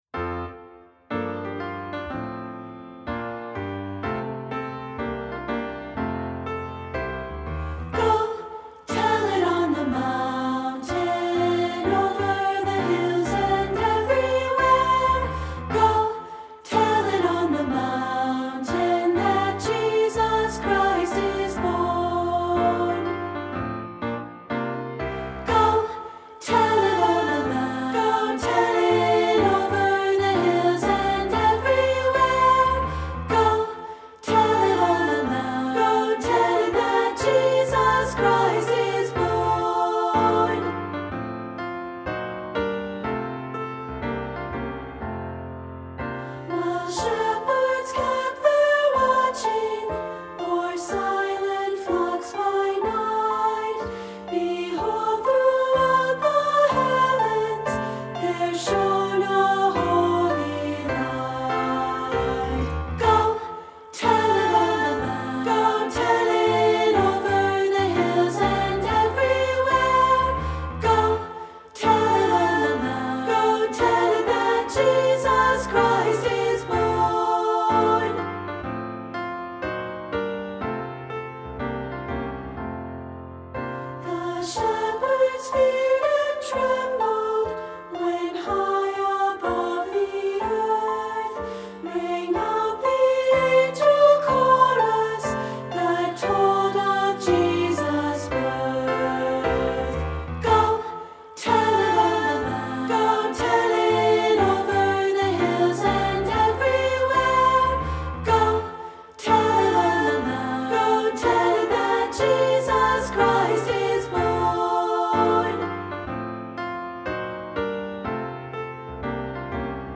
Unison/two with piano